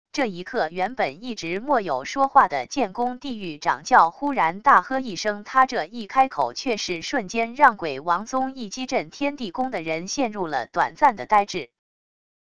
生成语音 下载WAV